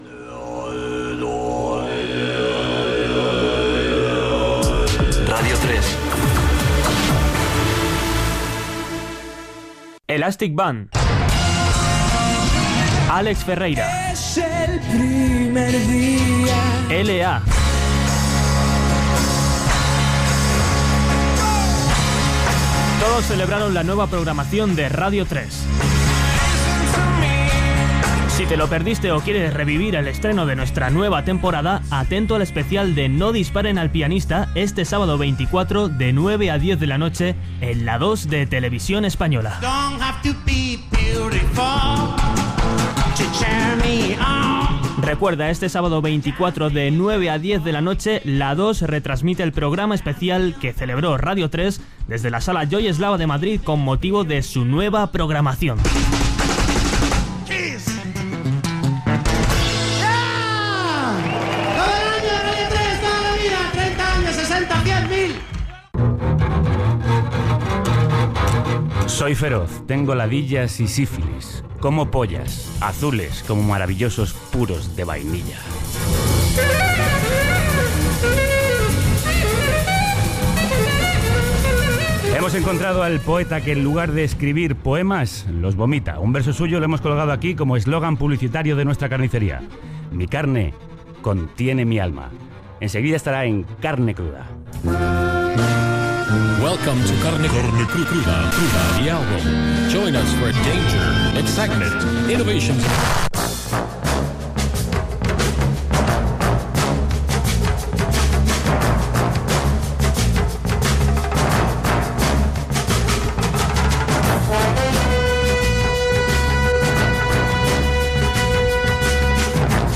Indicatiu de l'emissora, promoció "No disparen al pianista", careta del programa, presentació, sumari del programa